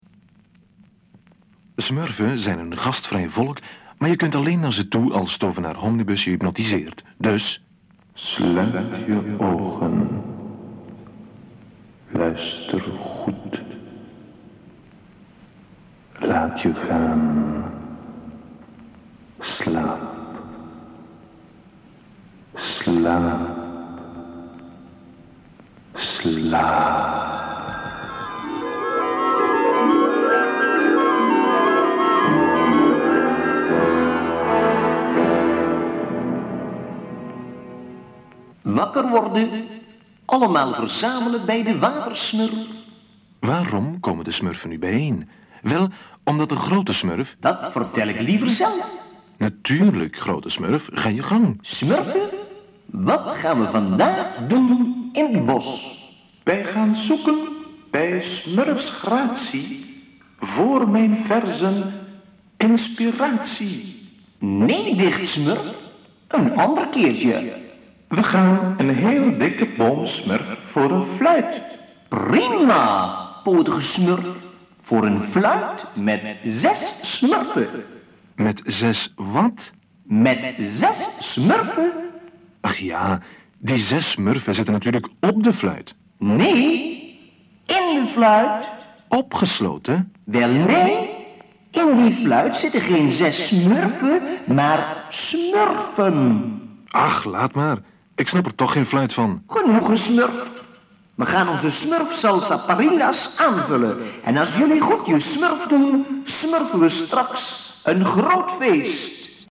Vertelling en muziek